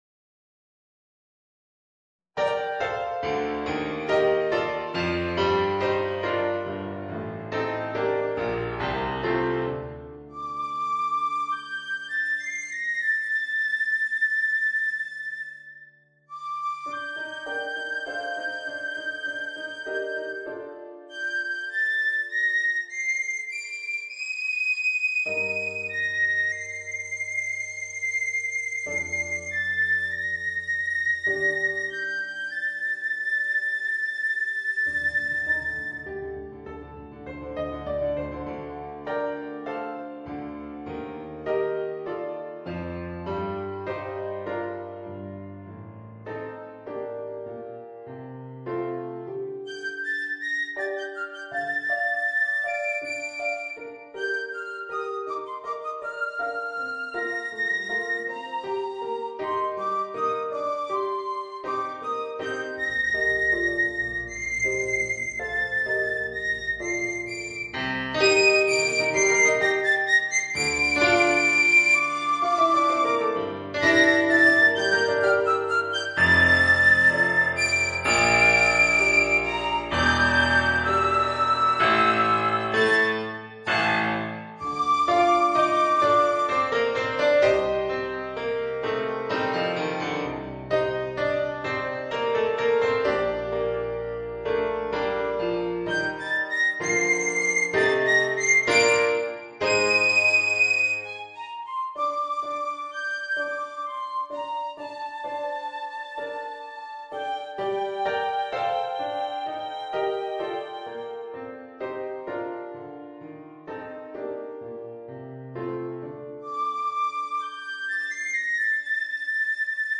4 Trombones et Orgue